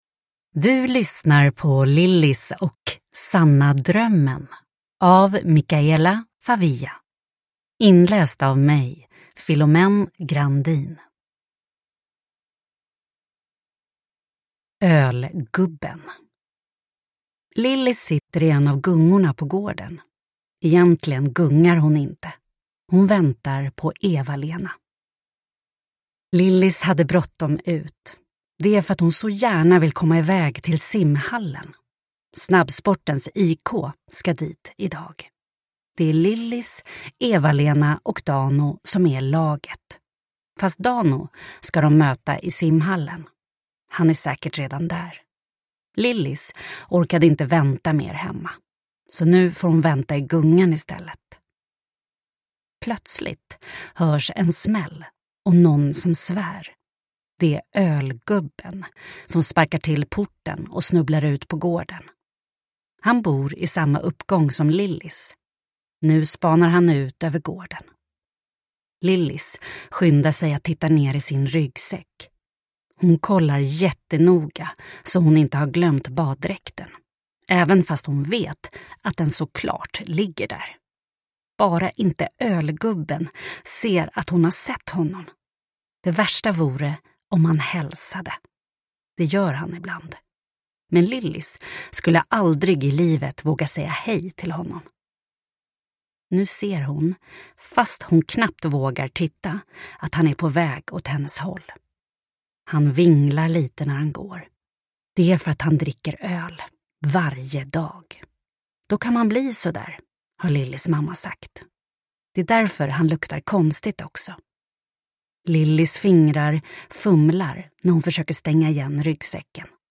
Lillis och sanna drömmen – Ljudbok – Laddas ner